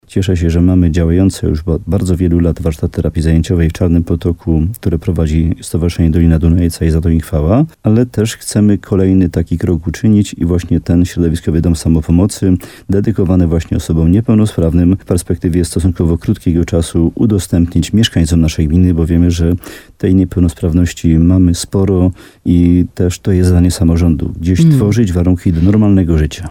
– Dom bardzo ułatwi funkcjonowanie niepełnosprawnych i ich rodzin – mówi wójt Łącka Jan Dziedzina.